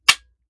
7Mag Bolt Action Rifle - Dry Trigger 001.wav